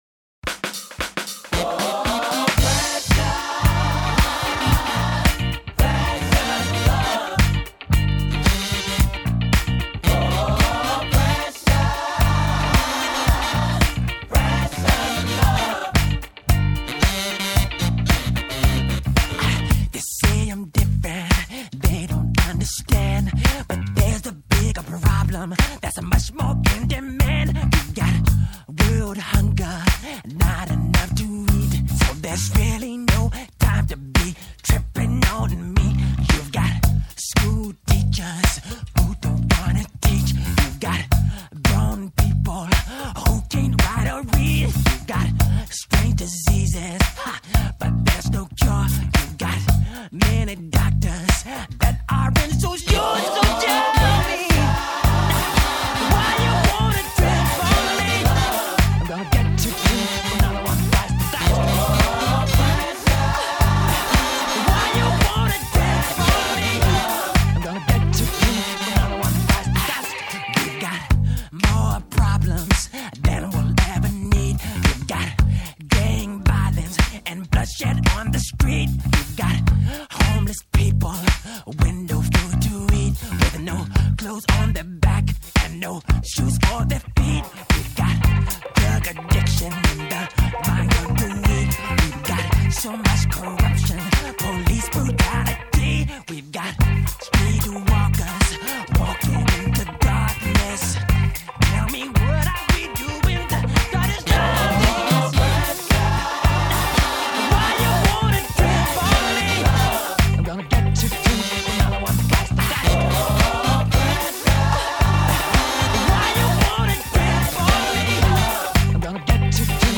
Acapella
Instrumentale